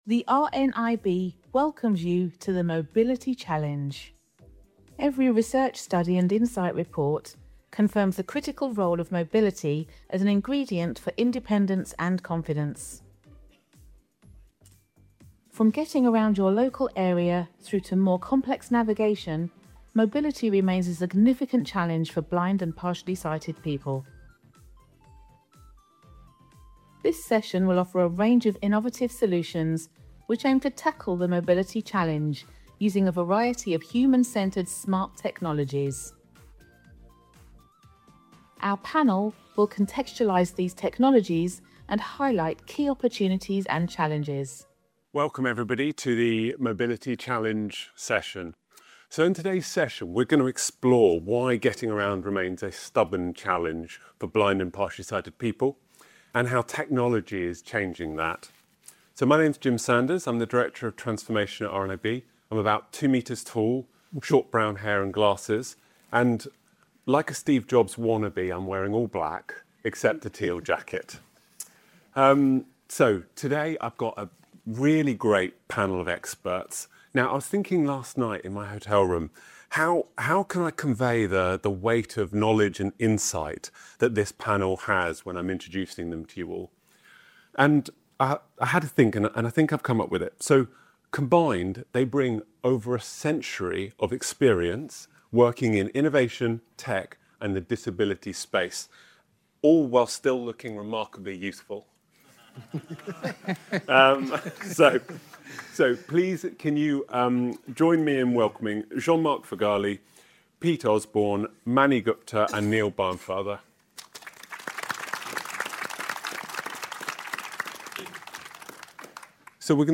The Mobility Challenge session took place on day two of RNIB Scotland's Inclusive Design for Sustainability Conference.